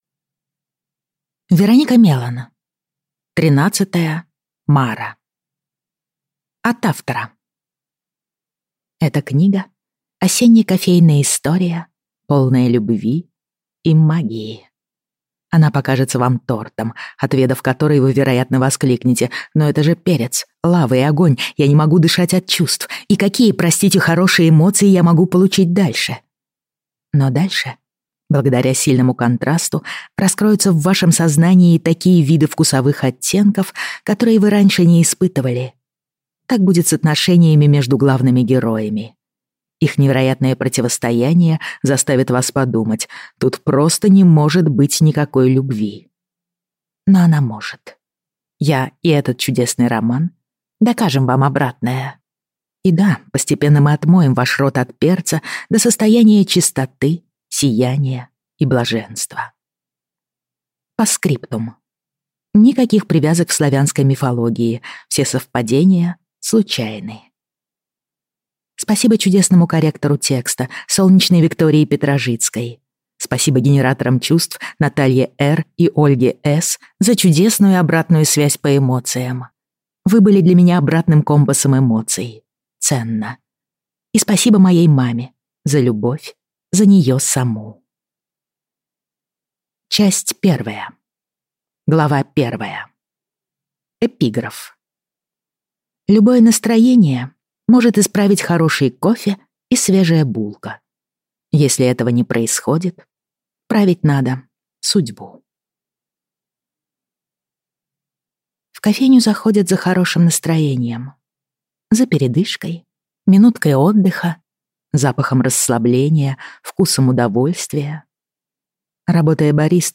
Аудиокнига Тринадцатая Мара | Библиотека аудиокниг